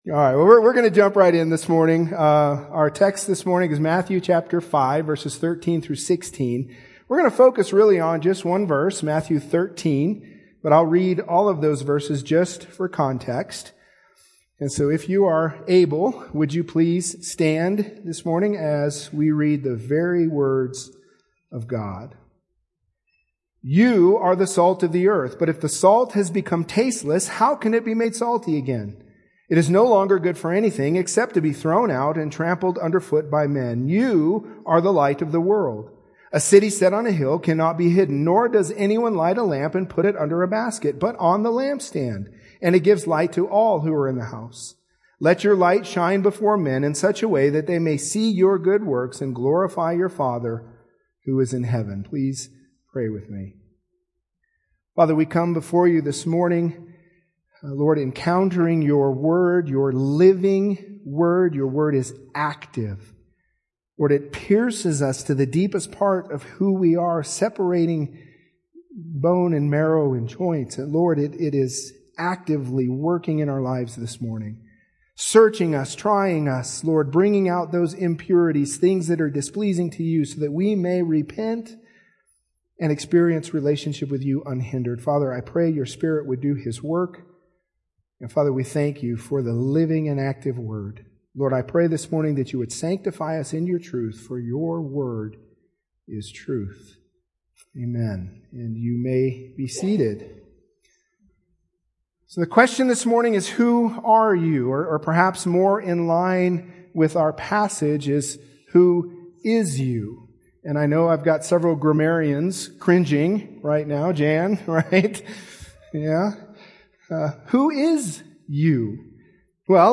Sermons – Vista Grande Baptist Church